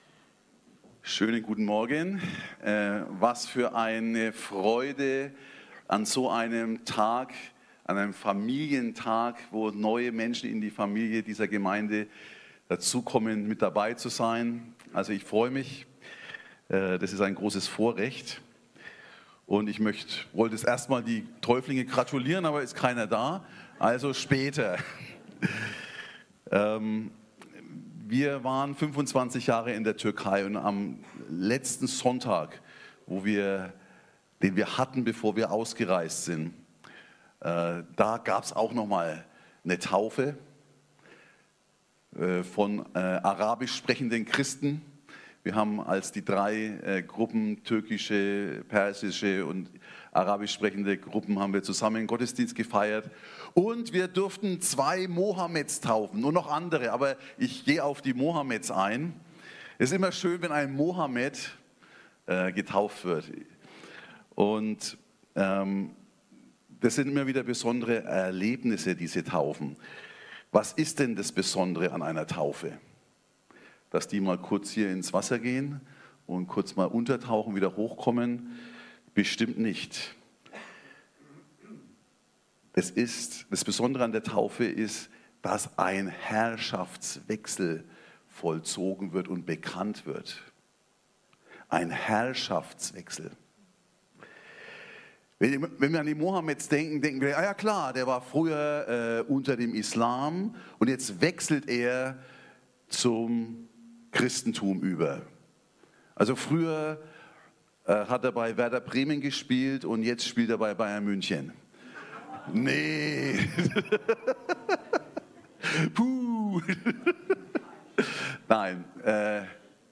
Taufgottesdienst ~ Predigten der OASIS Kirche Lilienthal Podcast